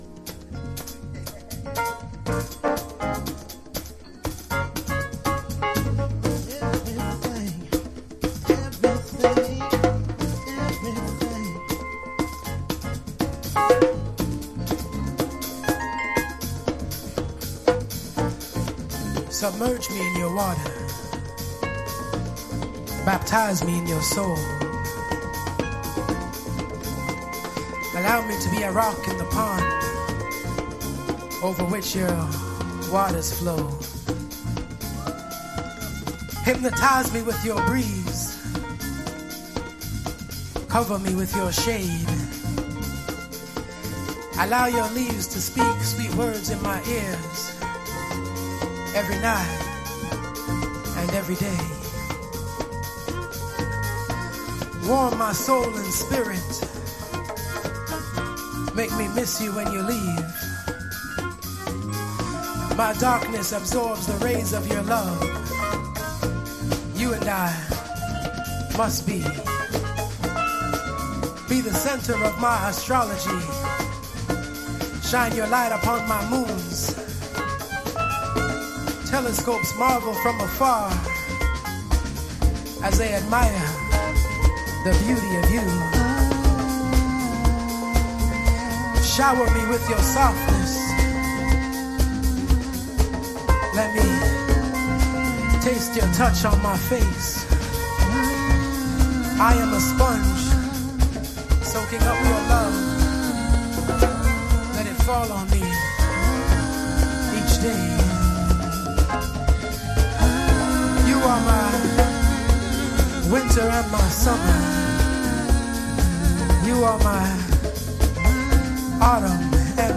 House / Techno
NY DEEP HOUSE / 70's BLACK JAZZとの親和性をグイグイ感じることができます。